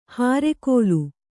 ♪ hāre kōlu